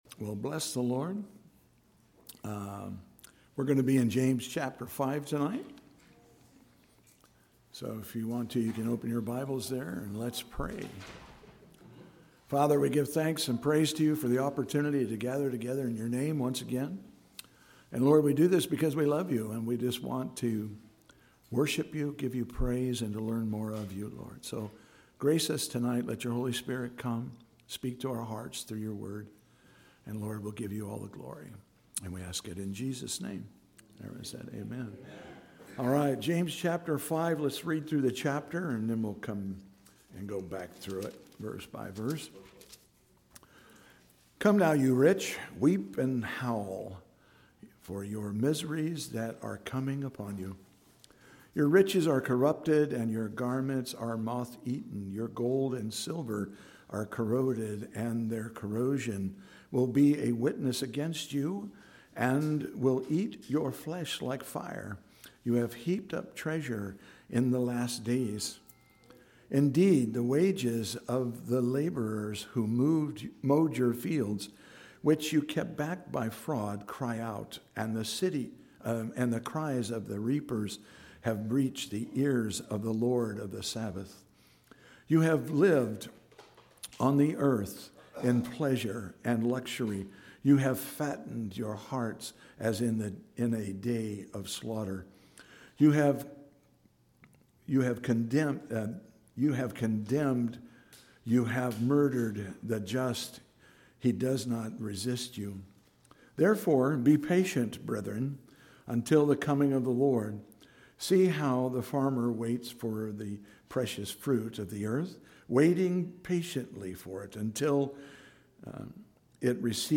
CCS Sermons